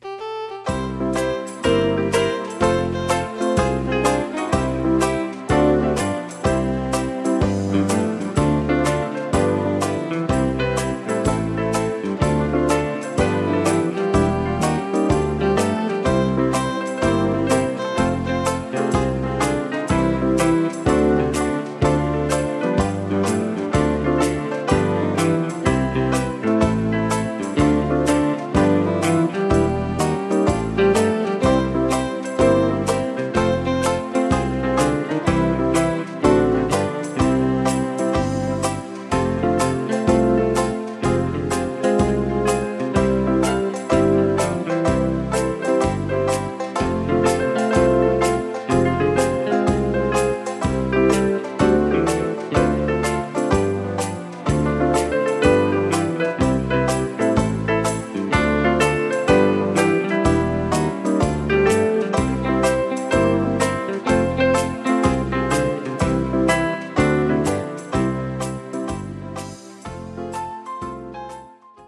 (Instrumental)
Singing Calls